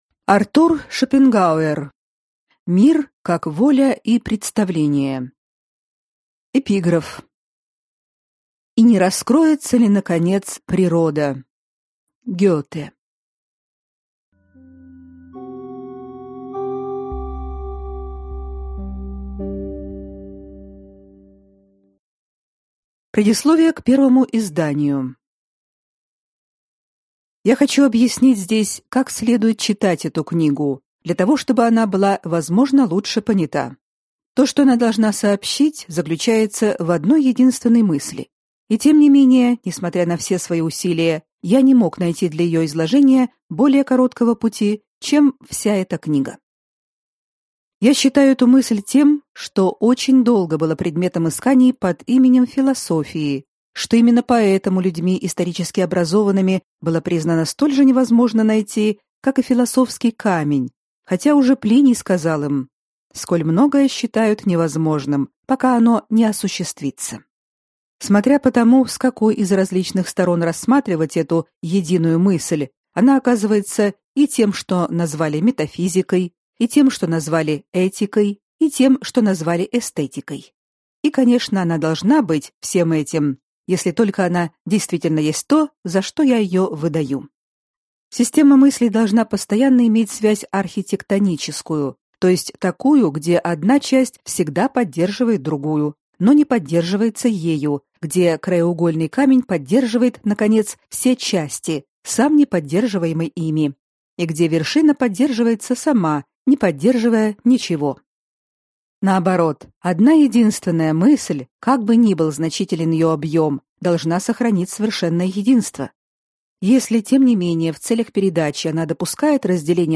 Аудиокнига Мир как воля и представление | Библиотека аудиокниг